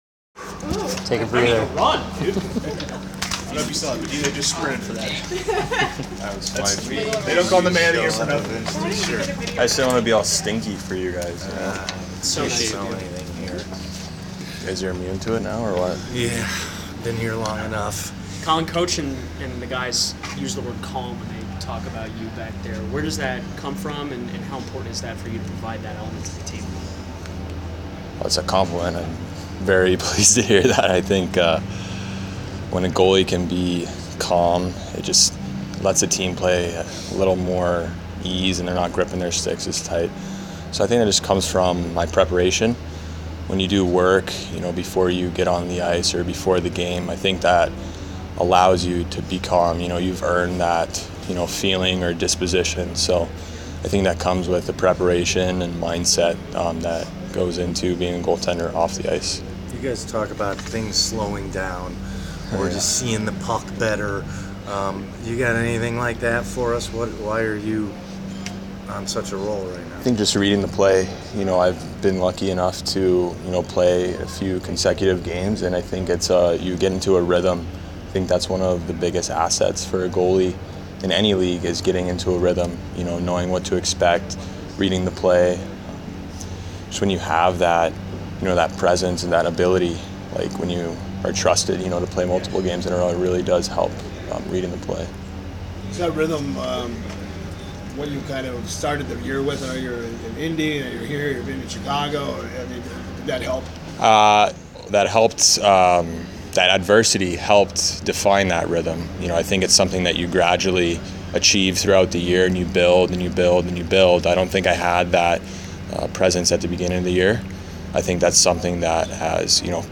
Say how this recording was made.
On Tuesday, the Rockford IceHogs held media availability ahead of their Game Three matchup with the Manitoba Moose. Rockford IceHogs Media Day Audio: May 8, 2018